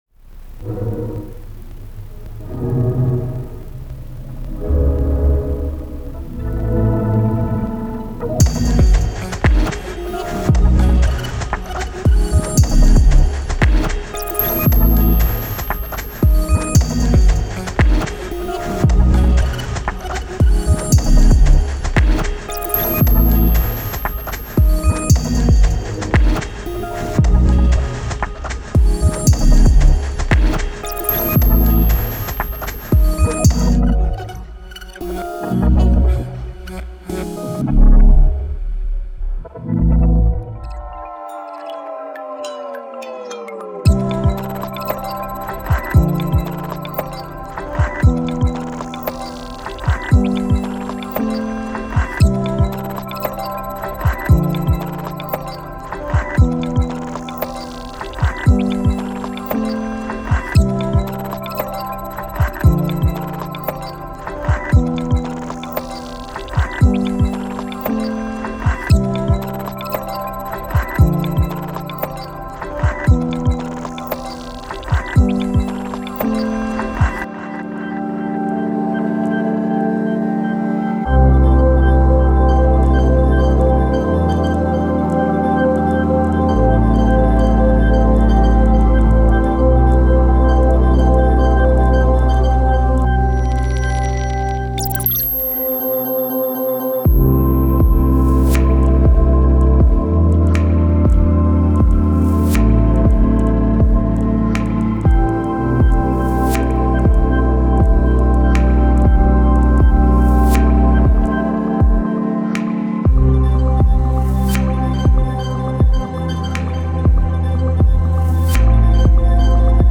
Genre:IDM
詳細なパーカッション、進化するテクスチャー、そして合成されたエネルギーを体験してください。
デモサウンドはコチラ↓